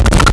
Player_Glitch [70].wav